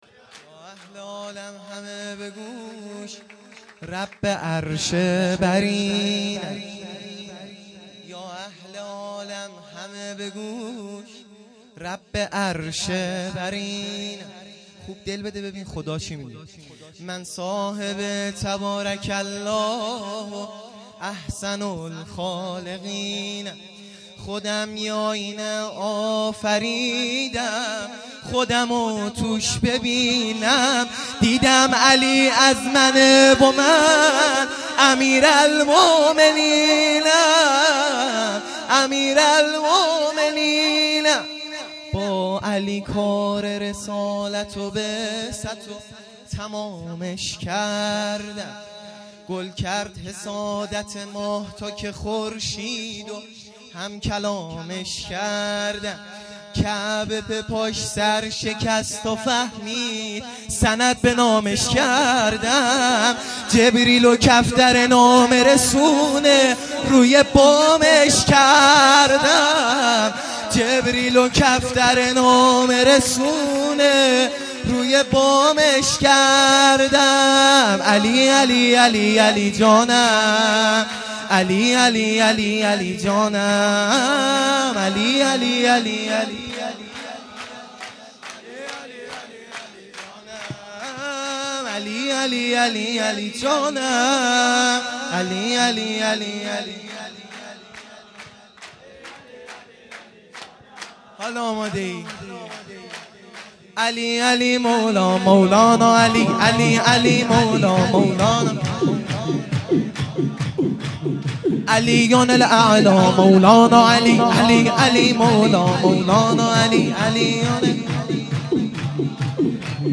0 0 سرود
میلاد امام هادی علیه السلام